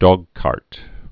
(dôgkärt, dŏg-)